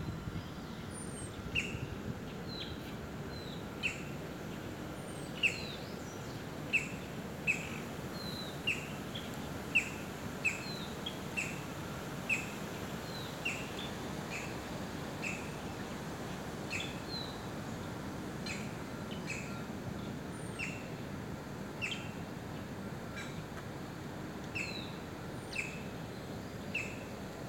Scientific name: Colaptes melanochloros melanolaimus
English Name: Green-barred Woodpecker
Life Stage: Adult
Detailed location: Eco Área Avellaneda
Condition: Wild
Certainty: Photographed, Recorded vocal